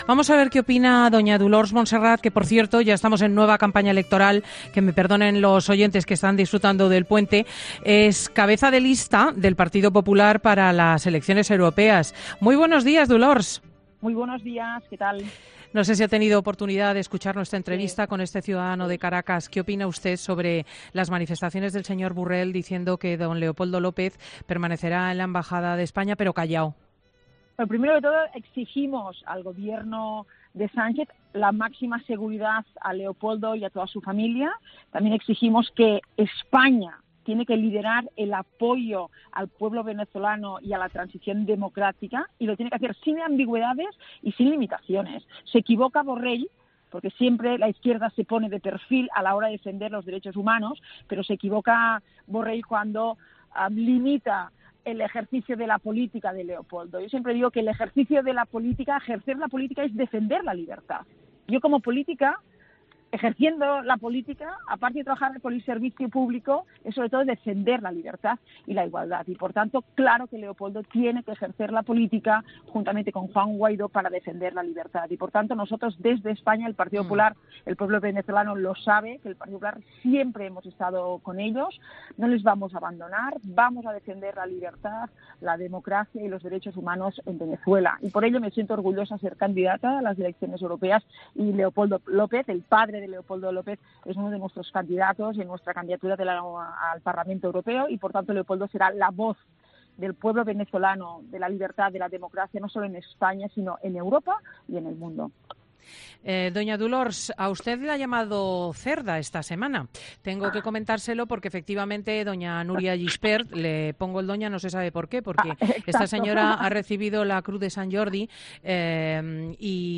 La popular ha criticado en 'Fin de Semana' la postura de Borrell en Venezuela.